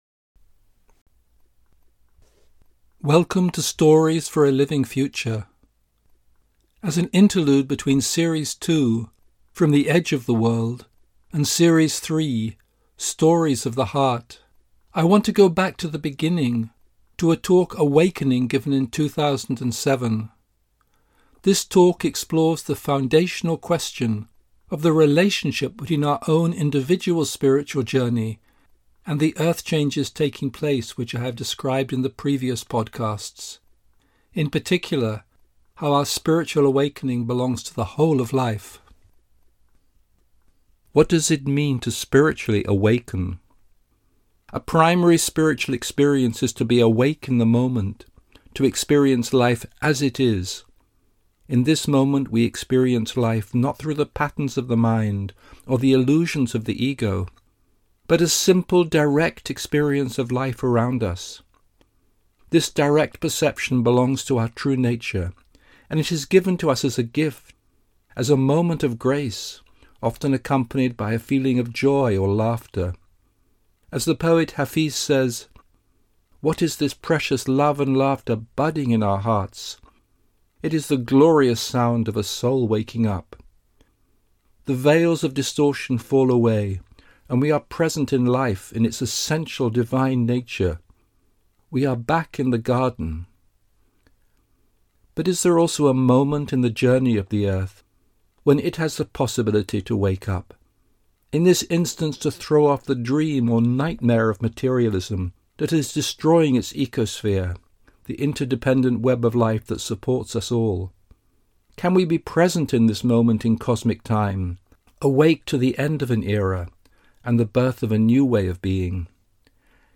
As an interlude between Series 2, From the Edge of the World, and Series 3, Stories of the Heart, I want to go back to the beginning, to a talk “Awakening” given in 2007. This talk explores the foundational question of the relationship between our own individual spiritual journey and the Earth changes taking place which I have described in the previous podcasts, in particular how our spiritual awakening belongs to the whole of life.